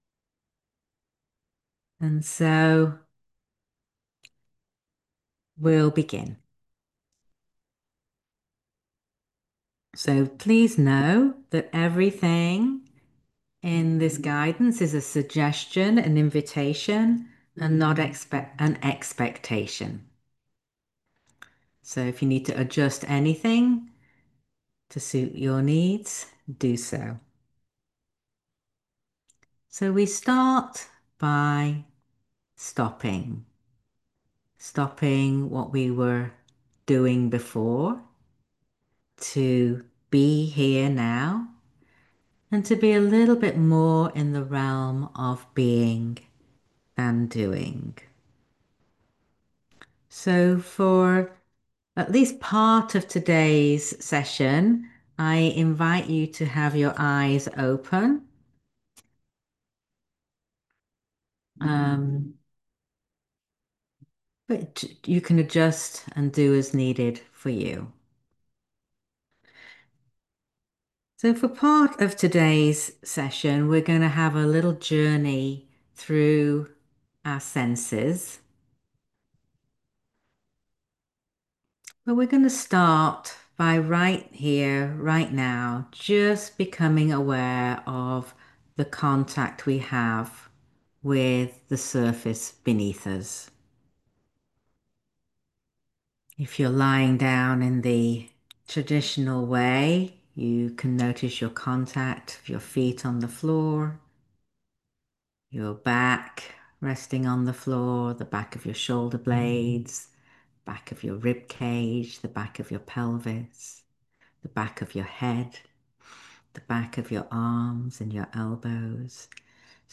talk given during a live session on July 31, 2025